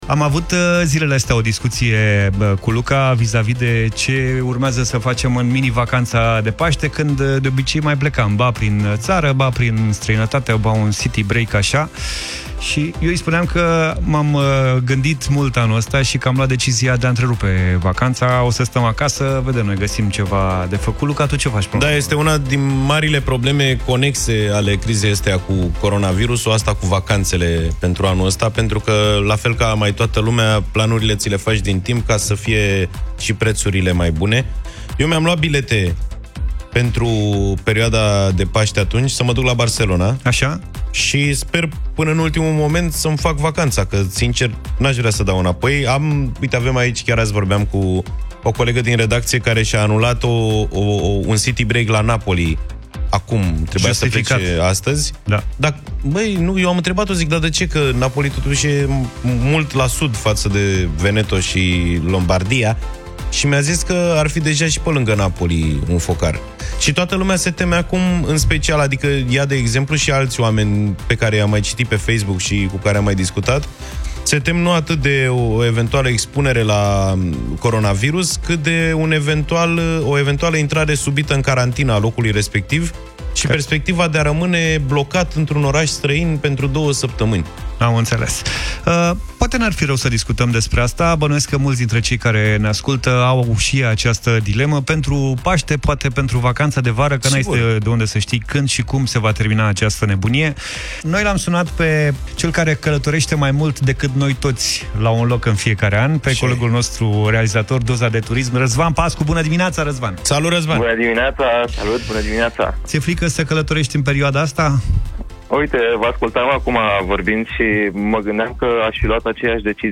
Dezbaterea dimineții: Renunțați la concediu de teama coronaviruslui?